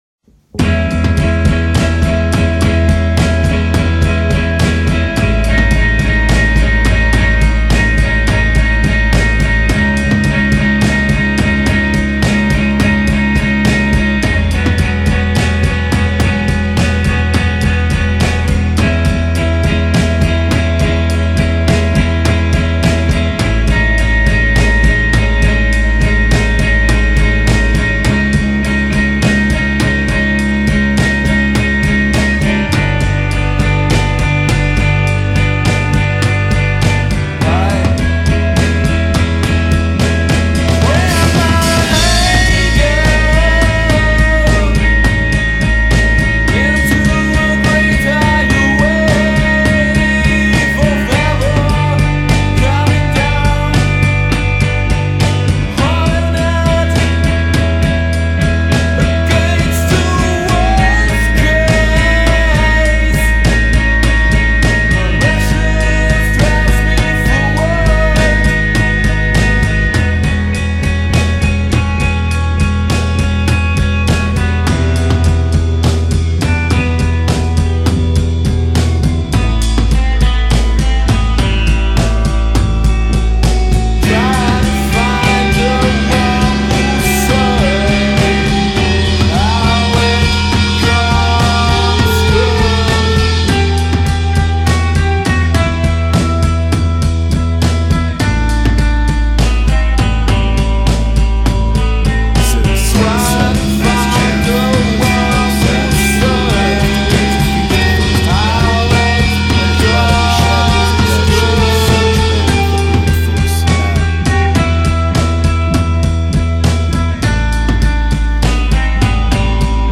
cheap recording
from the rehersal room